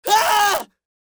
Screams Male 01
Screams Male 01.wav